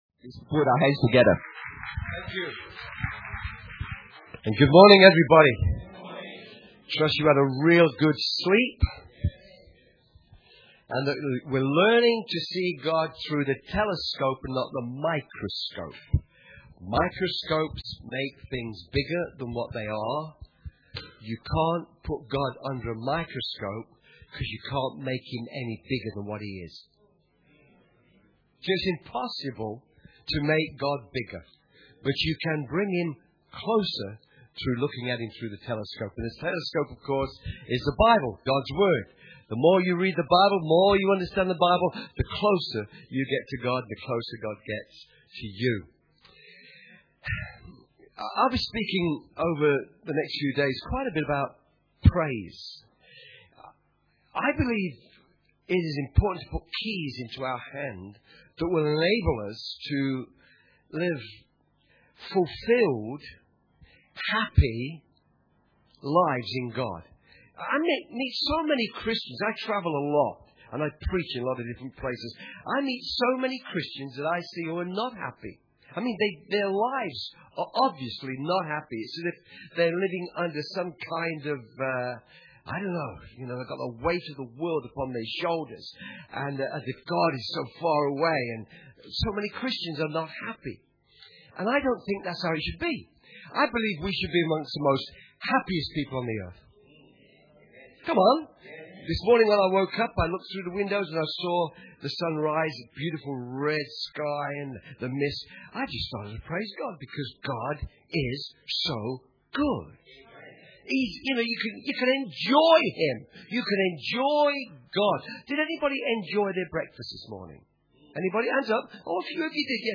Church Camp 2014 – Friday_Morn_Session1
Church Camp 2014 - Friday_Morn_Session1.mp3